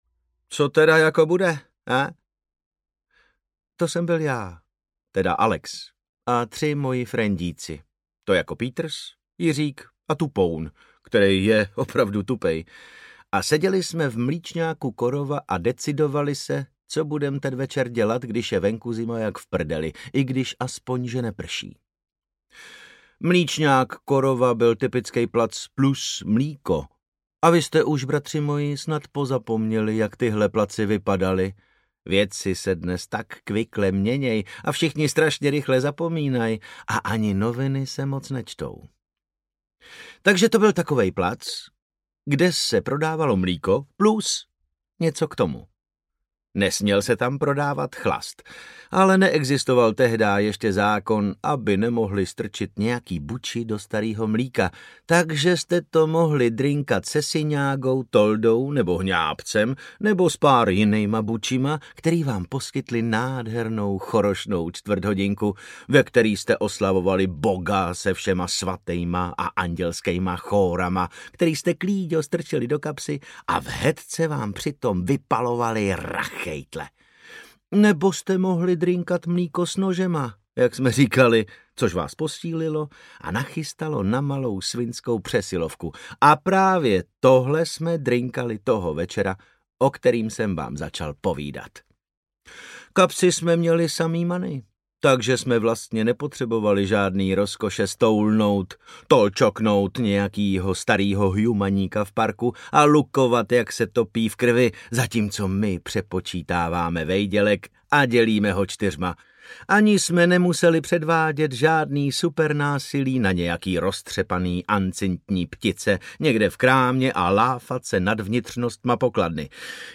Mechanický pomeranč audiokniha
Ukázka z knihy
• InterpretDavid Novotný
mechanicky-pomeranc-audiokniha